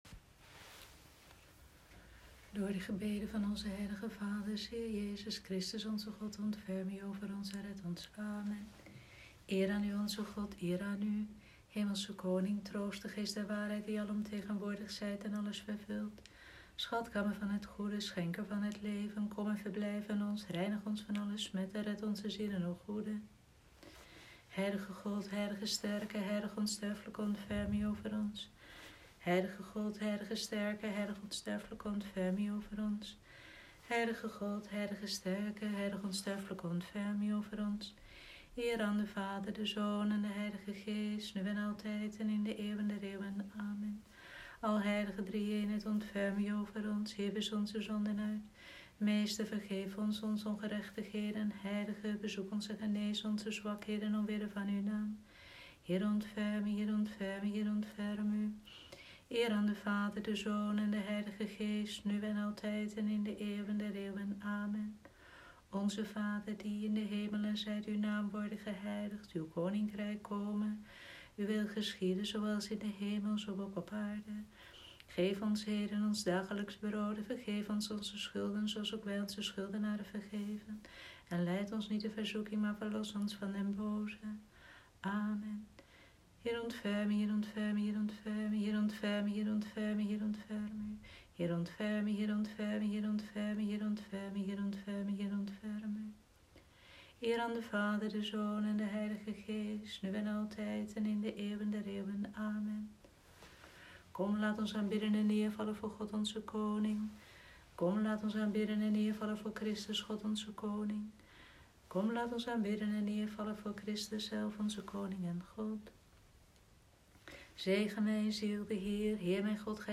Vespers Maandagavond, 6 April 2020
Vespers-maandagavond-6-april.m4a